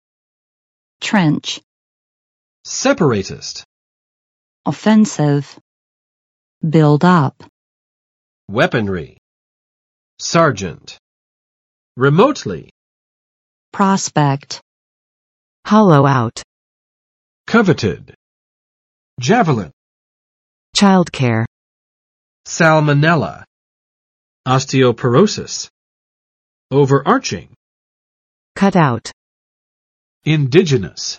[trɛntʃ] n. 战壕
trench.mp3